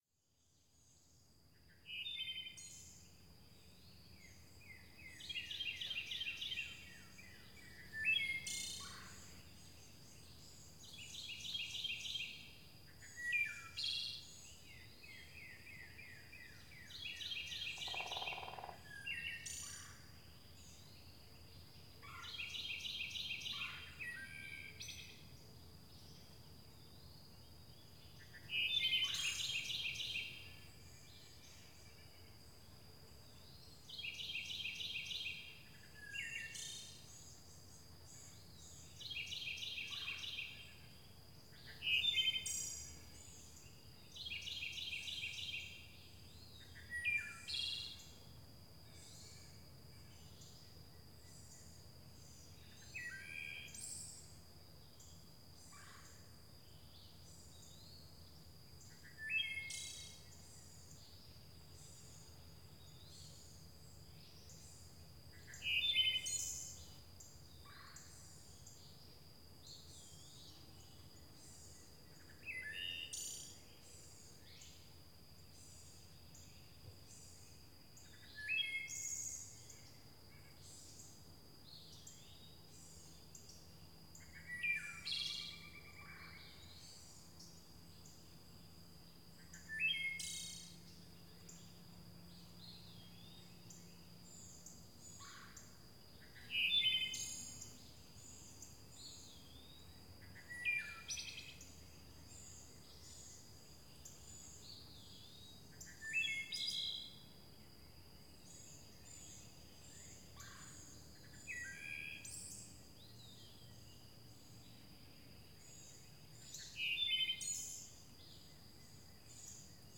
forest.ogg